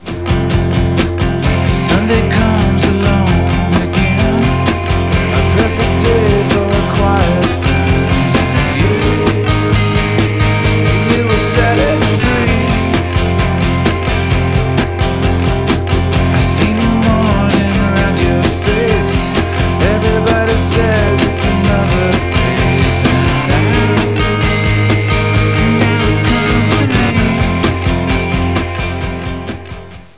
noise-guitar innovators and post-punk prodigies